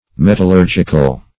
Metallurgic \Met`al*lur"gic\, Metallurgical \Met`al*lur"gic*al\,